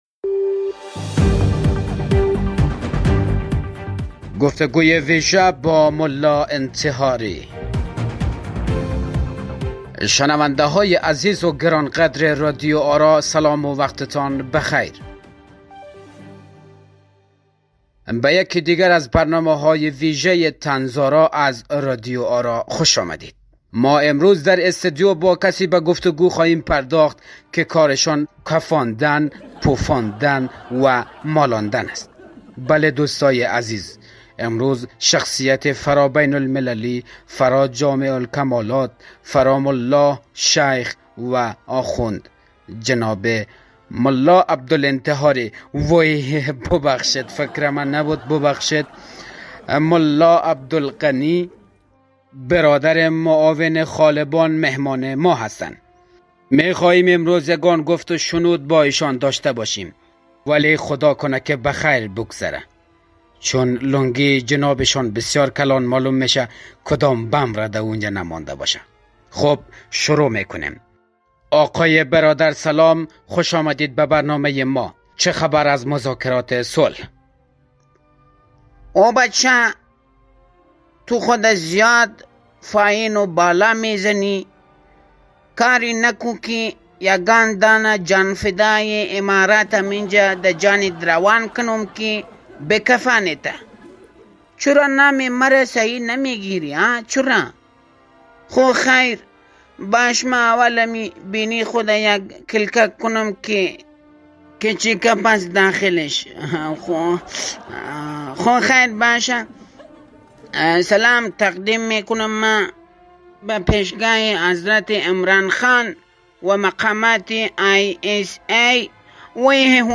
پادکست «طنز آرا» با اجراي تيمي از استندآپ كمدين ها و طنز پردازان خوب افغانستاني تهيه مي شود